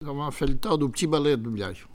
Localisation Perrier (Le)
Langue Maraîchin
Catégorie Locution